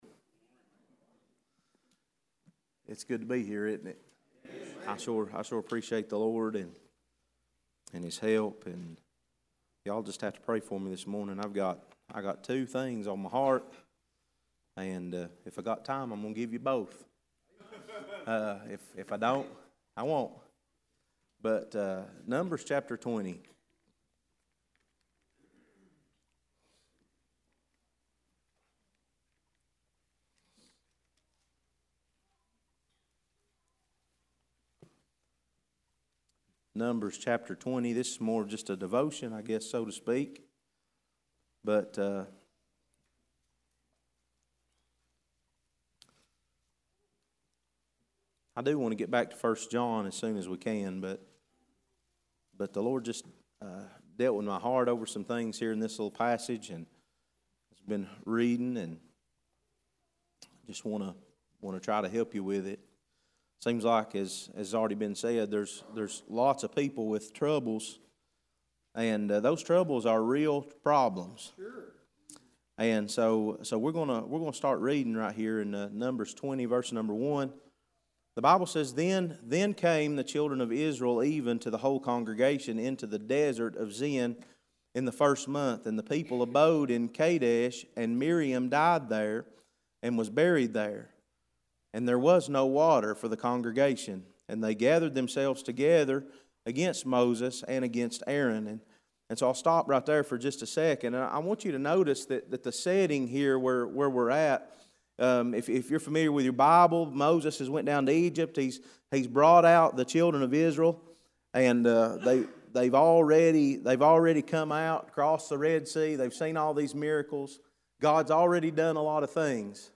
Passage: Numbers 20:1-11 Service Type: Sunday School